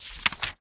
bookturn1.ogg